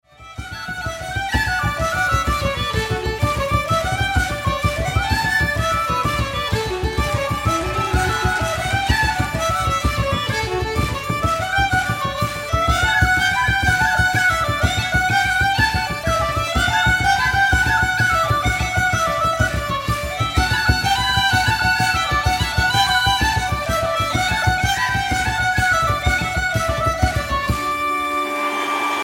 STYLE: Pop
Traditional Irish Reel